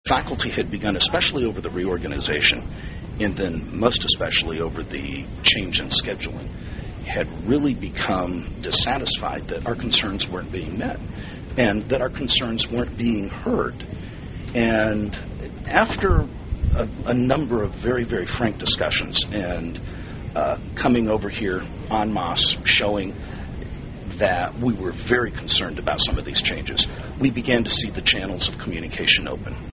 There was another spirited discussion on the Blinn College restructuring plan at last (Tuesday) night’s meeting of the Board of Trustees, with the Board President answering concerns and conflicts and a large contingent of faculty and staff still expressing dissatisfaction with the plan.